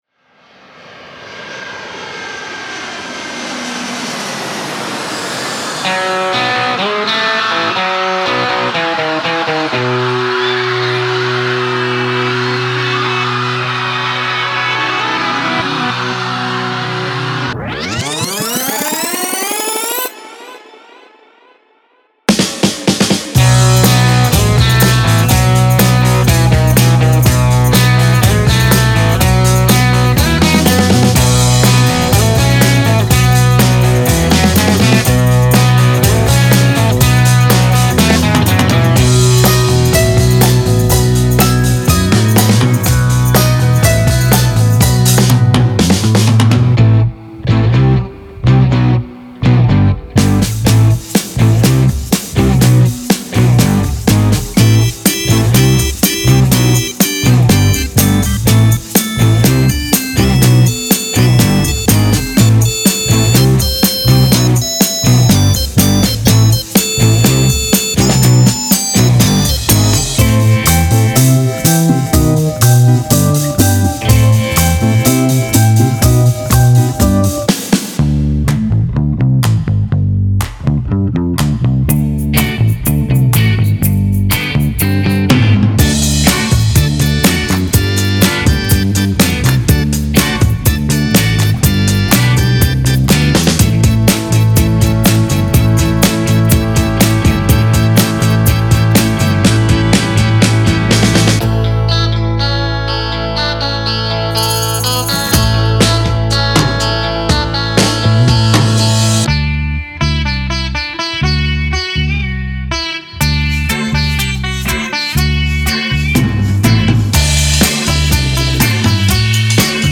Genre:Rock
デモサウンドはコチラ↓